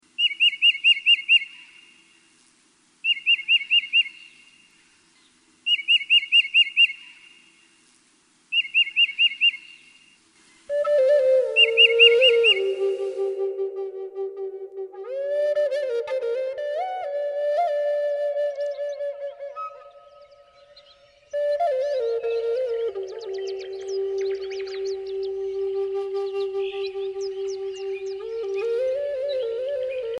World/New Age